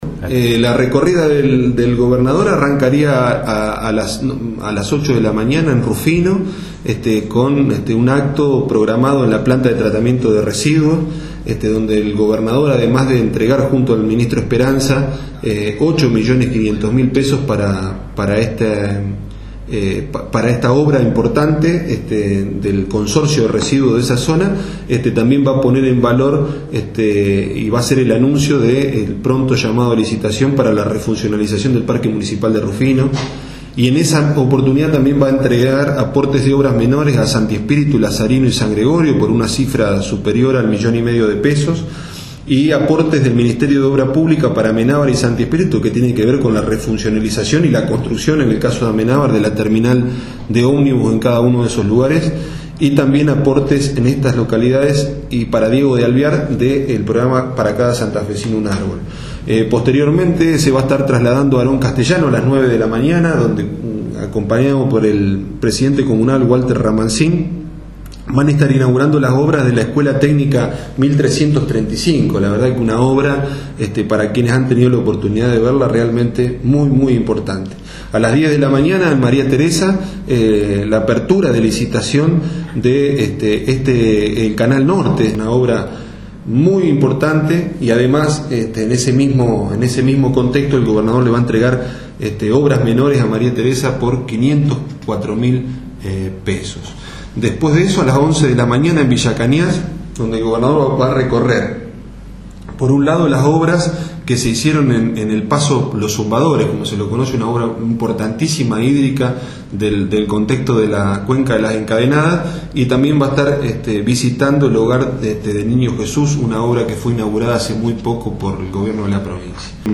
El coordinador de la Región 5 Diego Milardovich informó con detalles las actividades del Gobernador Miguel Lifschitz mañana viernes en su recorrida por diversas localidades de la región: